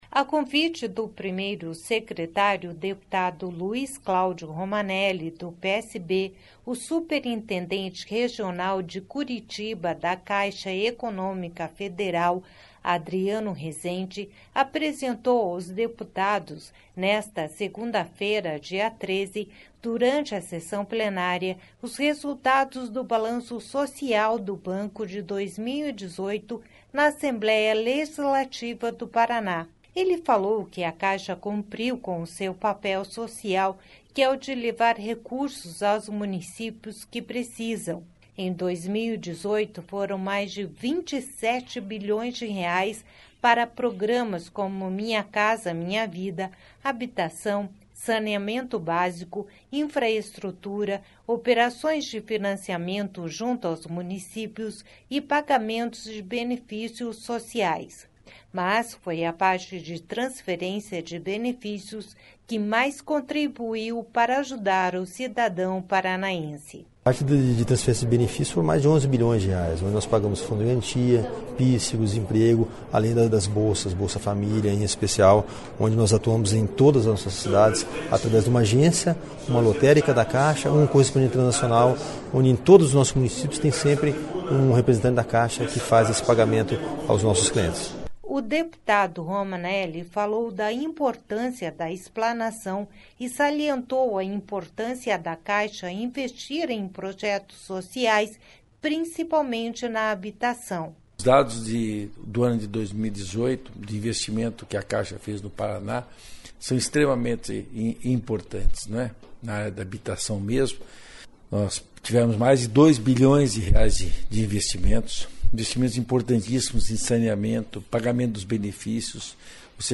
Sonora Romanelli -